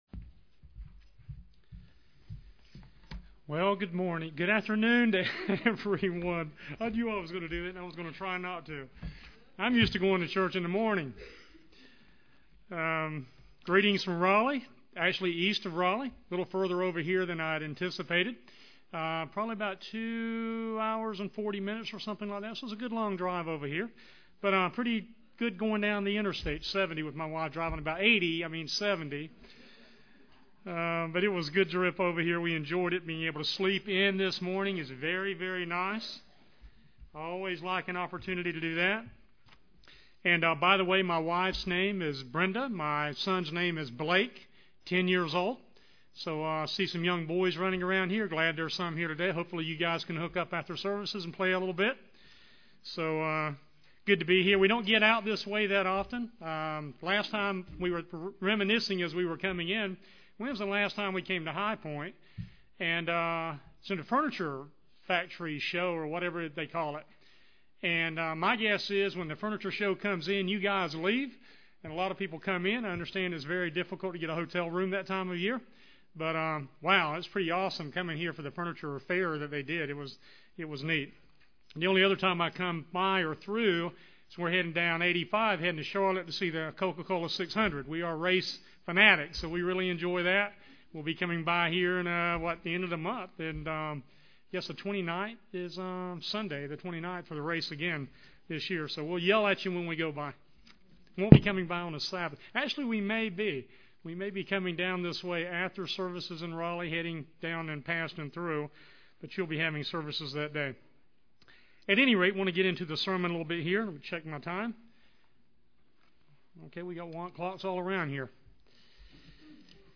Given in Greensboro, NC
UCG Sermon Studying the bible?